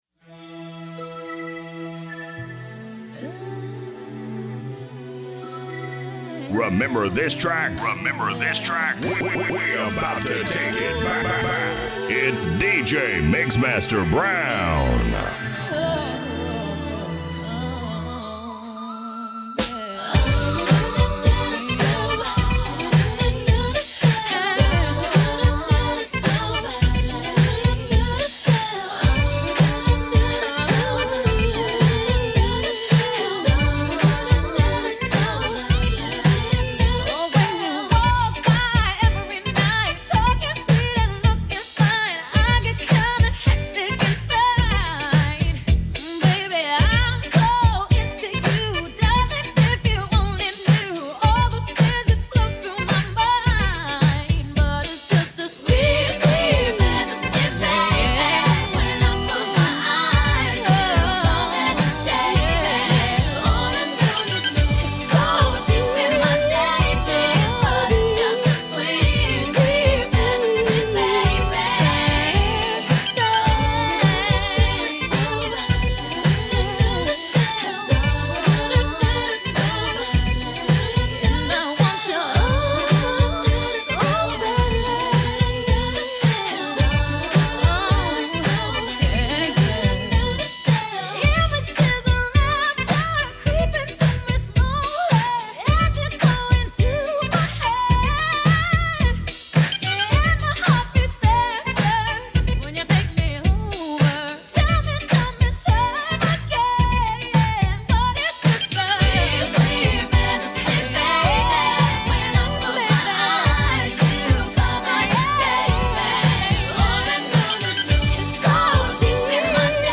afrobeat music dj mix